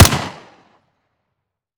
weap_uzulu_fire_plr_01.ogg